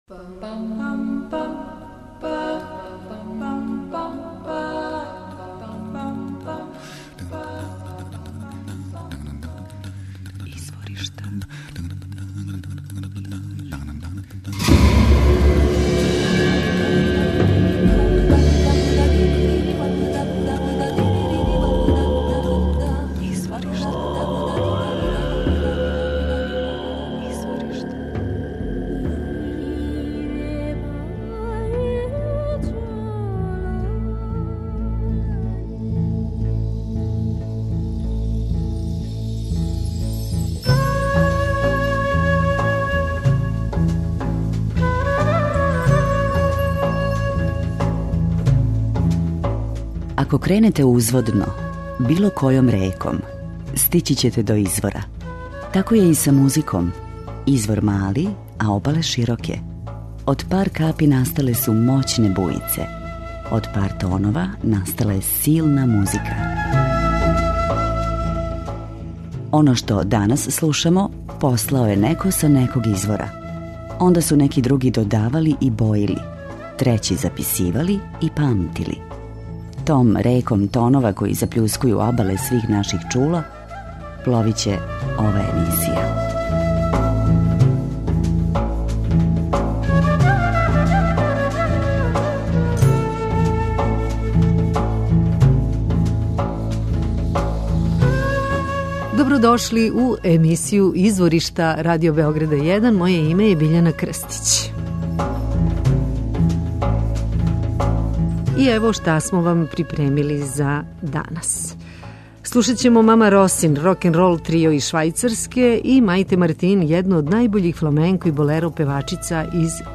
У њиховим композицијама се препознају утицаји зидека, ритам музике Њу Орлеанса и психоделичног транса.
Користе разне инструменте, ту су све врсте удараљки, виолина, хармонка, гитара...